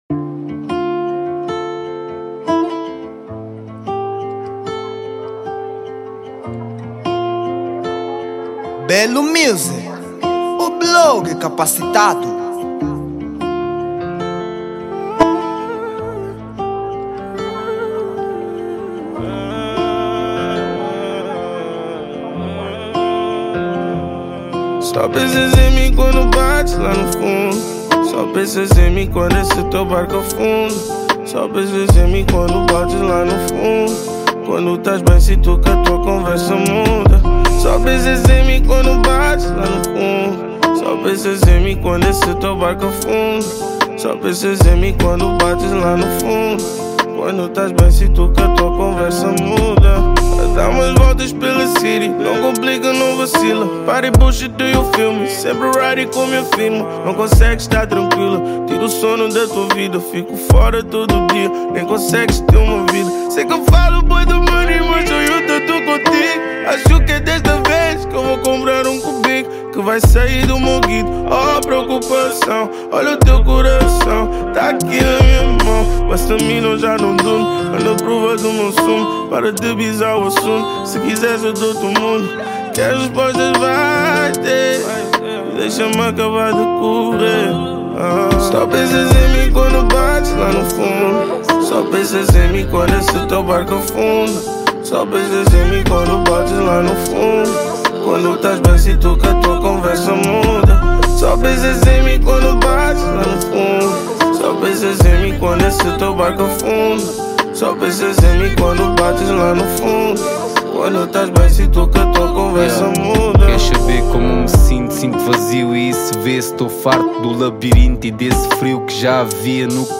mp3 Género: R&b Ano de lançamento